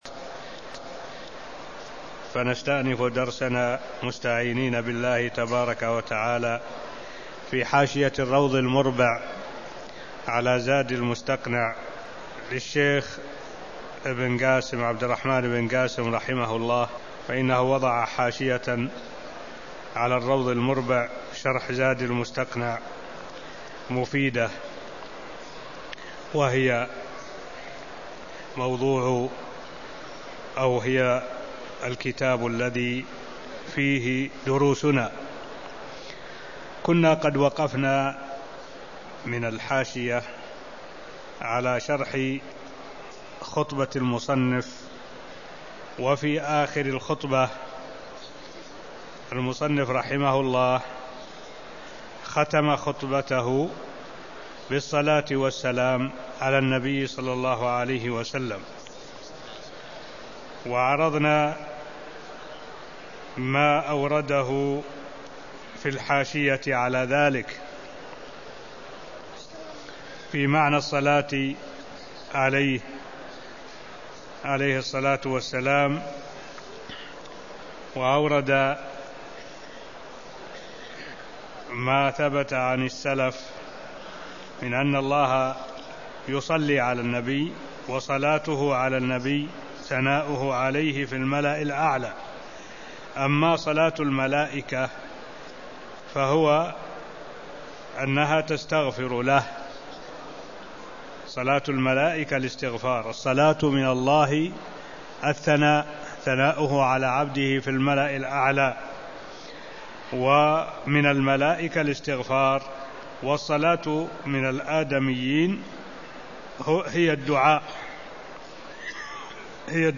المكان: المسجد النبوي الشيخ: معالي الشيخ الدكتور صالح بن عبد الله العبود معالي الشيخ الدكتور صالح بن عبد الله العبود خطبة المؤلف كتاب الطهاره (0008)